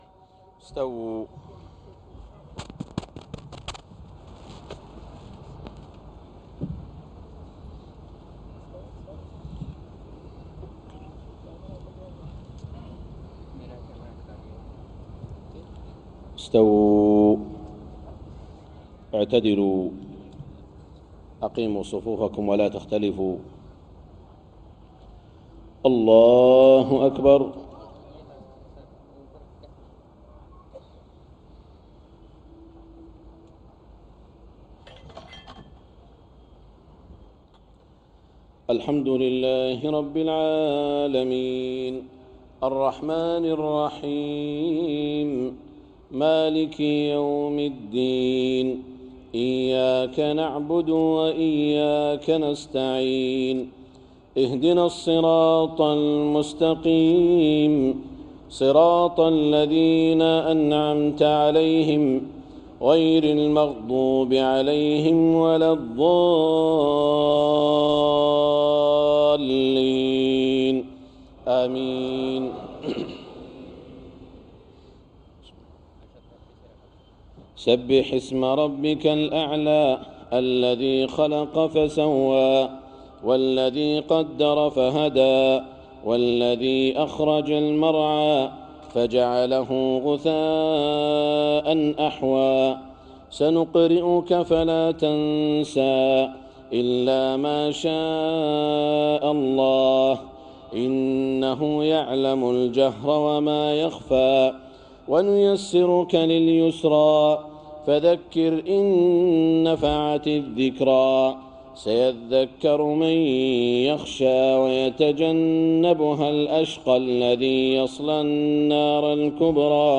تلاوة لسورتي الأعلى والغاشية صلاة الجمعة 29 ذو الحجة 1432هـ من الهند > زيارة الشيخ سعود الشريم لدولة الهند > تلاوات و جهود الشيخ سعود الشريم > المزيد - تلاوات الحرمين